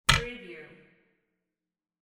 Cabinet door close sound effect .wav #3
Description: The sound of a cabinet door with magnetic catch being closed
Properties: 48.000 kHz 16-bit Stereo
Keywords: cabinet, door, close, closing, shut, shutting, magnet, magnetic, latch
cabinet-door-close-preview-3.mp3